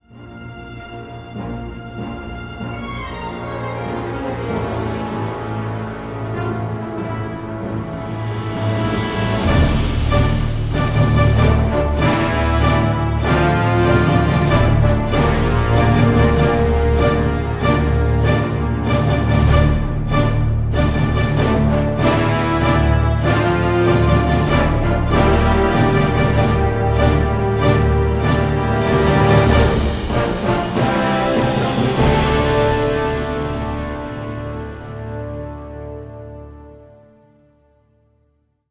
Adventure Brass是在洛杉矶的The Bridge录制的，这是一个杰出的评分阶段，自2010年开放以来，已经见证了各种电视，电影和游戏得奖项目。
我们记录了中等大小的部分；一个双重用途的小组，即使在动态层之间发生交叉淡入淡出时，也能以令人难以置信的透明度对均匀和谐波通道进行均等处理。
-分区大小：2个喇叭，4个角，2个长号，1个大号。
-以传统座位安排记录。
-三个麦克风位置：关闭，房间，混合。